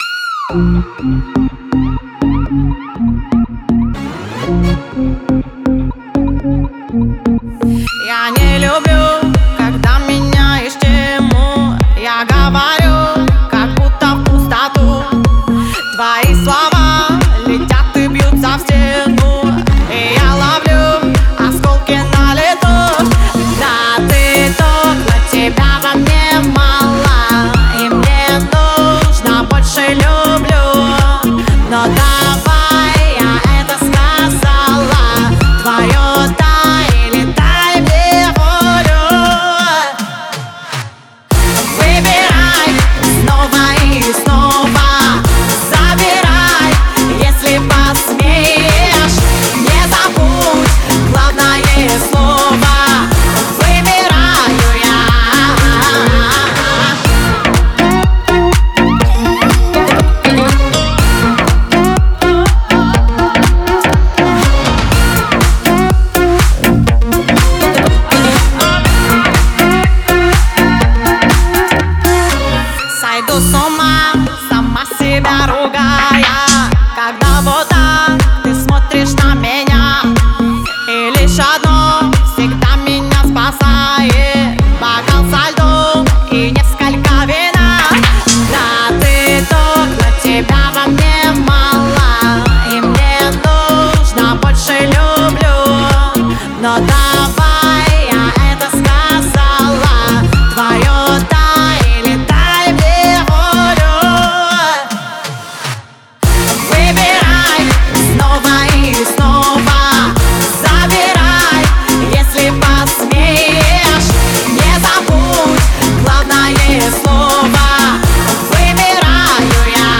мощный вокал и запоминающиеся мелодии